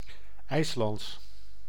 Ääntäminen
Synonyymit poney islandais poney d'Islande Ääntäminen France: IPA: /is.lɑ̃.dɛ/ Haettu sana löytyi näillä lähdekielillä: ranska Käännös Konteksti Ääninäyte Adjektiivit 1.